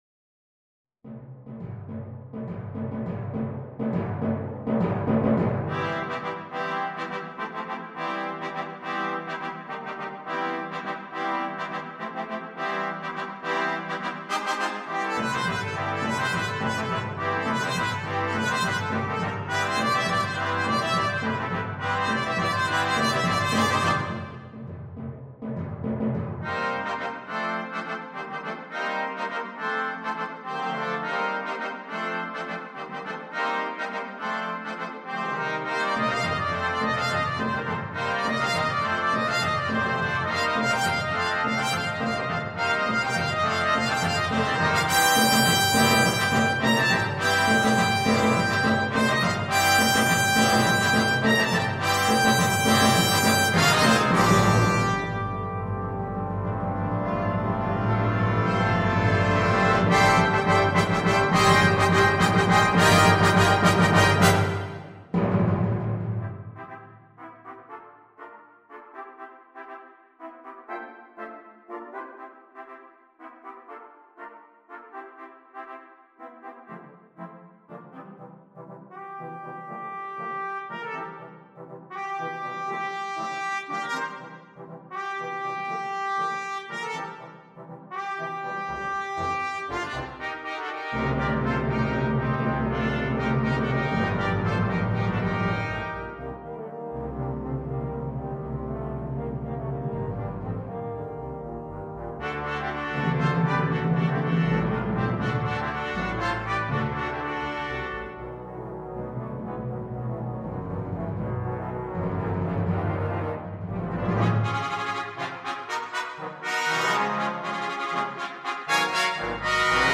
Ensemble - Mixed brass